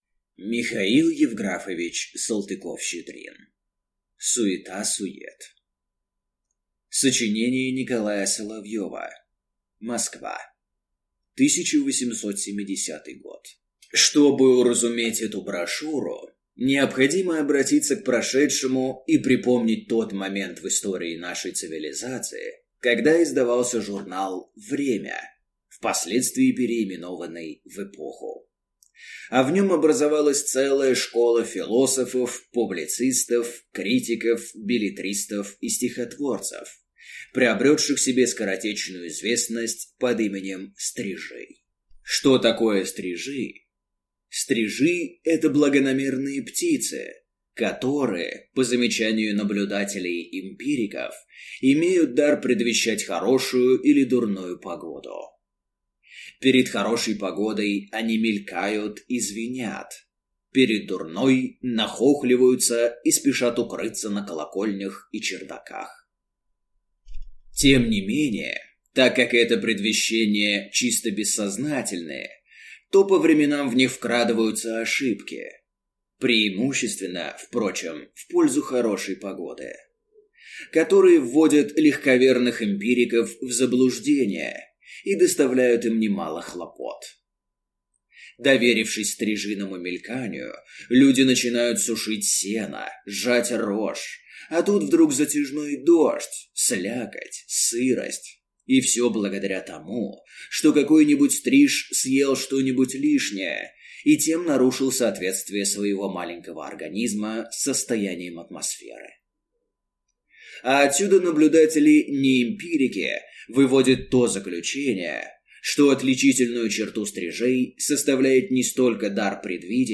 Аудиокнига Суета сует | Библиотека аудиокниг
Прослушать и бесплатно скачать фрагмент аудиокниги